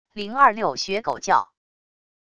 026学狗叫wav音频